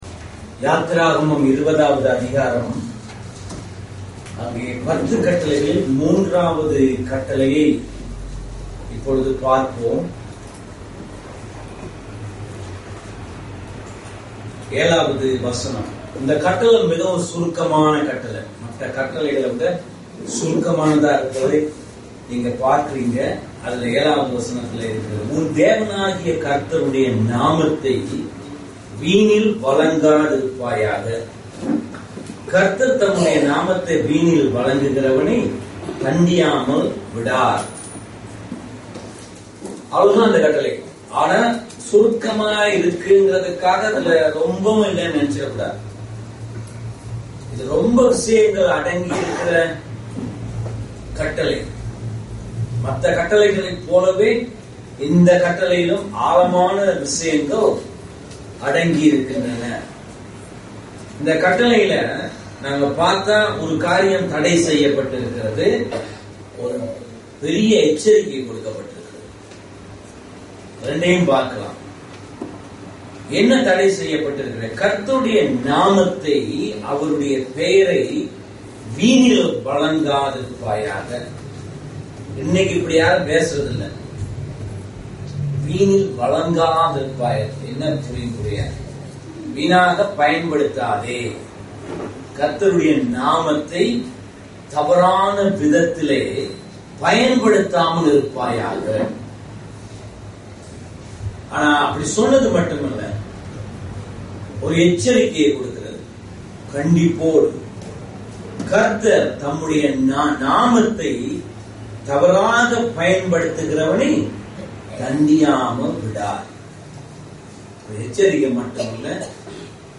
கீழ்வரும் பிரசங்கங்களை ஒலி (Audio) வடிவில் கேட்கலாம் & பதிவிறக்கம் (Download) செய்துக்கொள்ளலாம்.